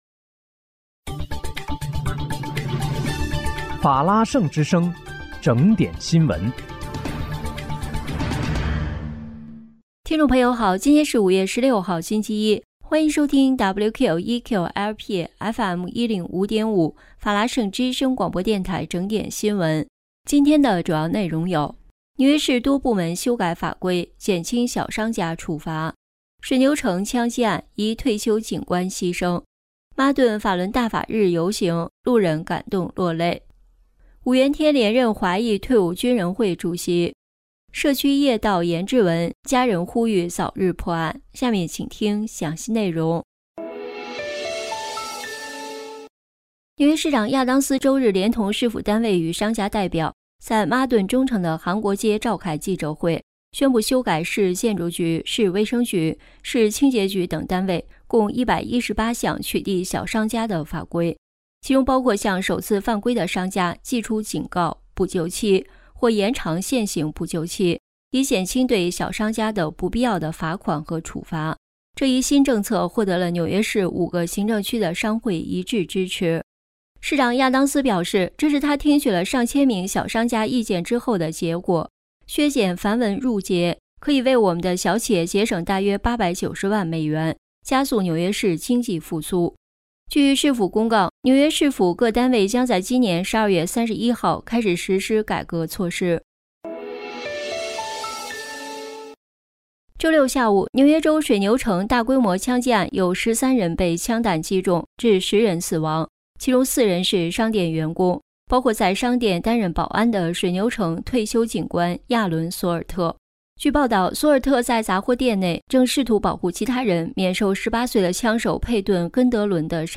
5月16日（星期一）纽约整点新闻
听众朋友您好！今天是5月16号，星期一，欢迎收听WQEQ-LP FM105.5法拉盛之声广播电台整点新闻。